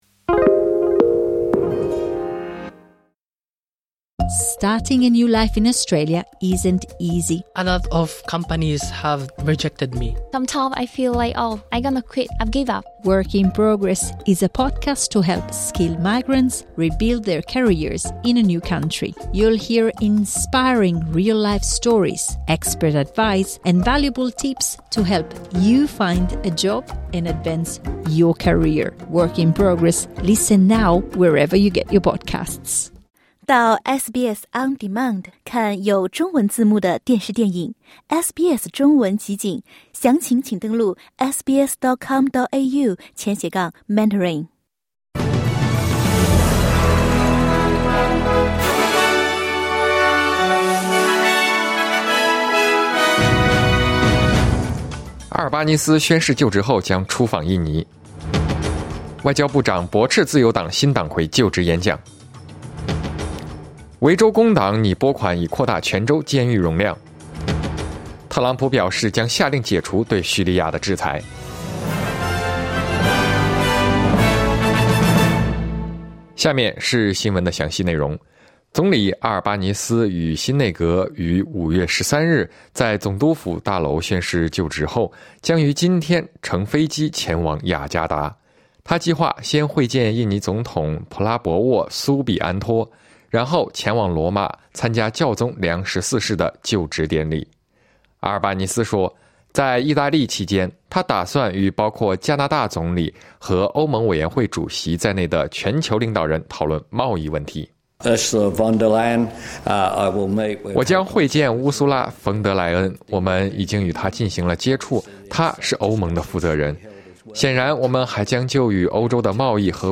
SBS早新闻（2025年5月14日）